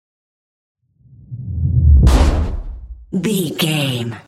Dramatic hit deep scary trailer
Sound Effects
Atonal
heavy
intense
dark
aggressive
hits